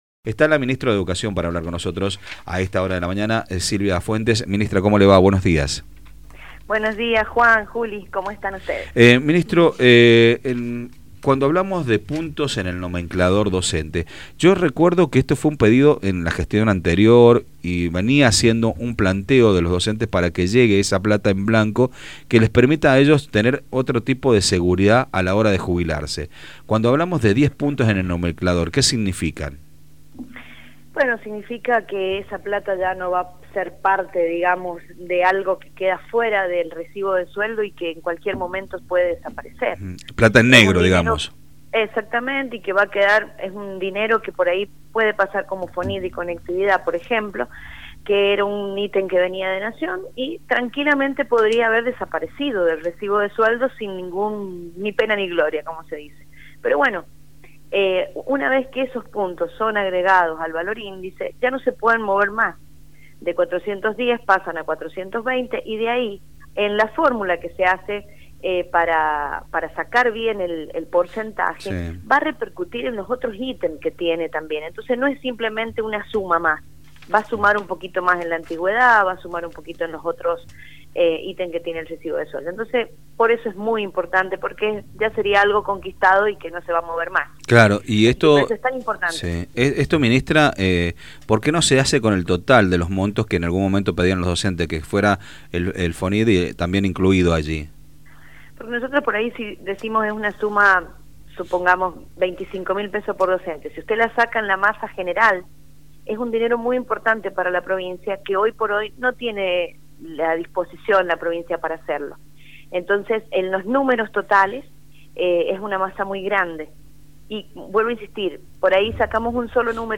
La ministra de Educación de la provincia, Silvia Fuentes, comentó que se está trabajando en la implementación de un plan de contingencia destinado a enfrentar la suspensión de clases, especialmente durante eventos climáticos como el viento Zonda. En una entrevista con Radio Sarmiento, la funcionaria detalló los aspectos clave de esta iniciativa que se desplegará en todo el territorio provincial.